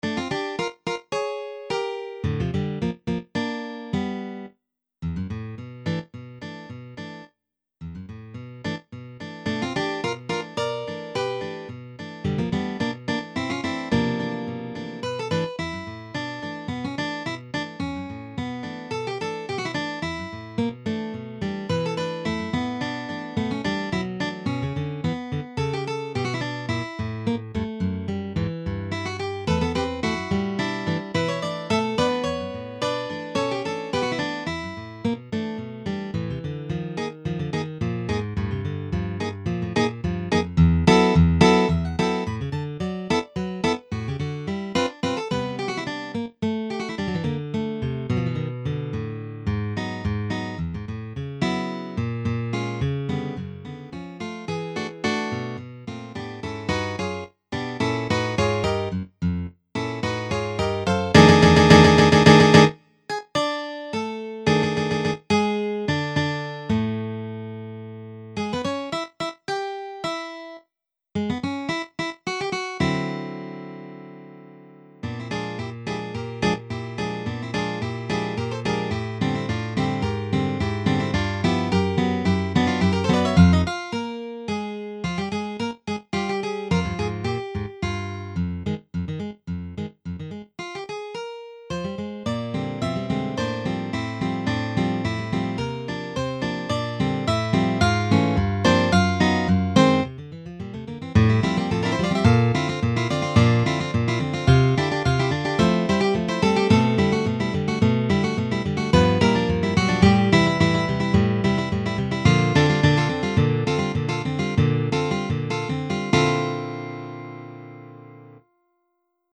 Fantasy